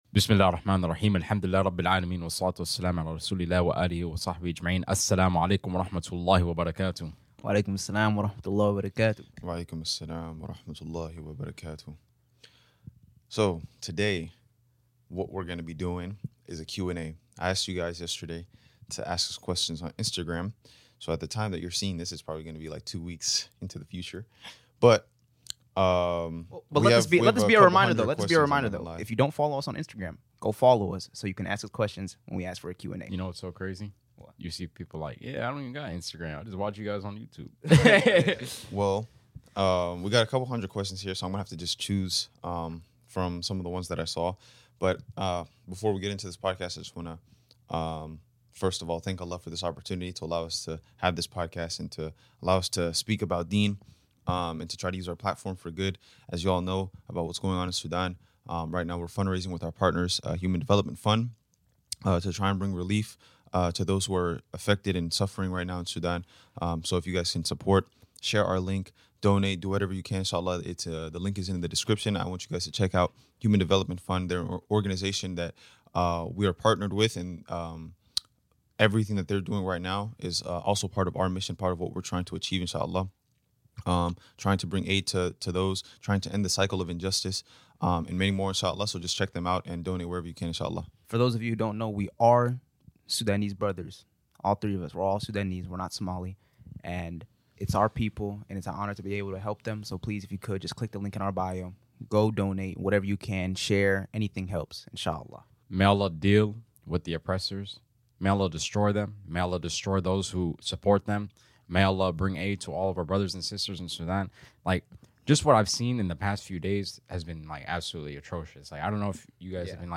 An unapologetically real podcast hosted by 3 muslim brothers.